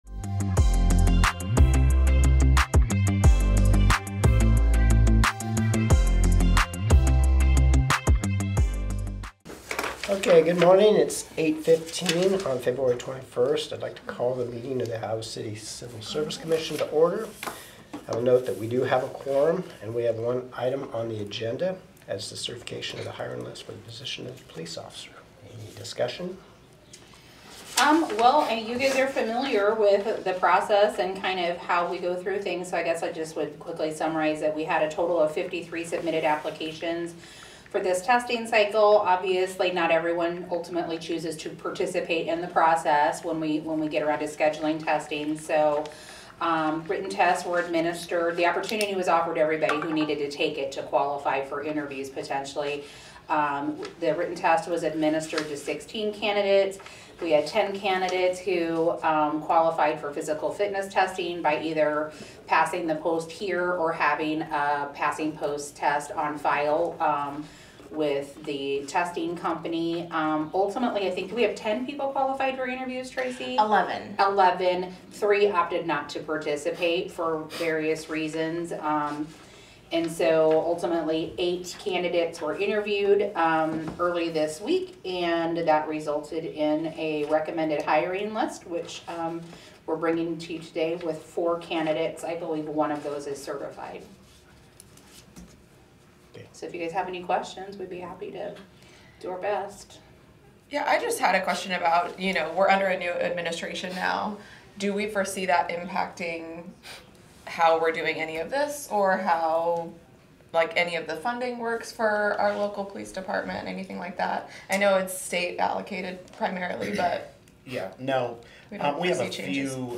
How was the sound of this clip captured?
A meeting of the City of Iowa City's Civil Service Commission.